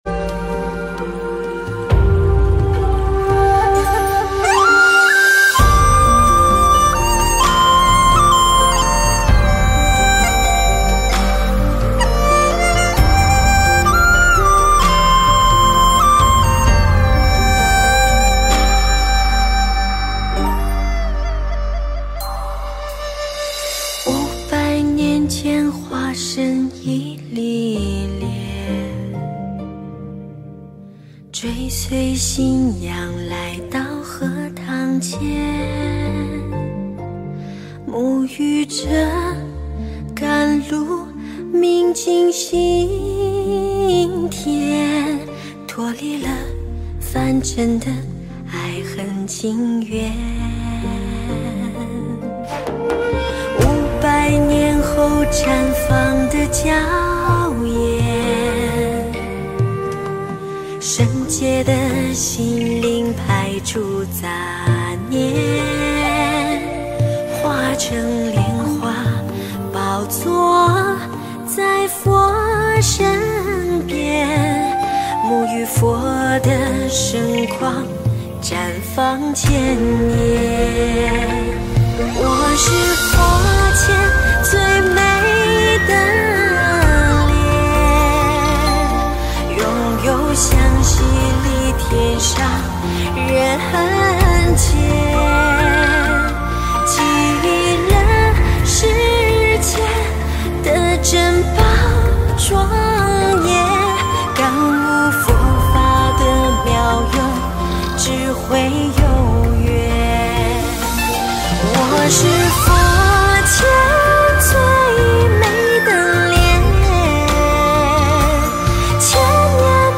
音频：多倫多觀音堂十週年慶典花絮！2023年01月04日